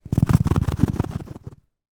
Все записи натуральные и четкие.
Шорох грызуна, скользящего по ковру